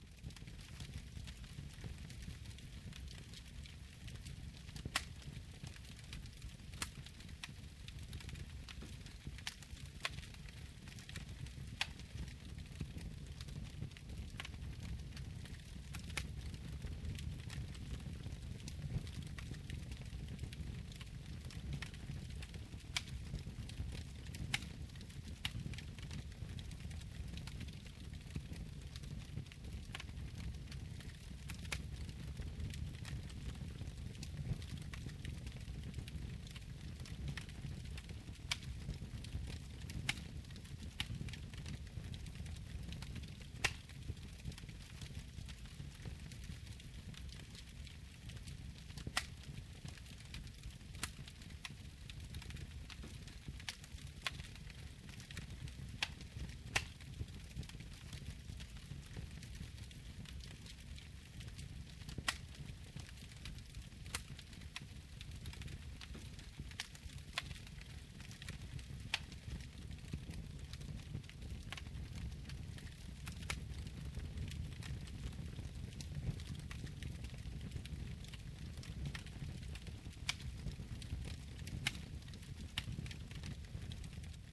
zvuk-kamina_zastolom.ogg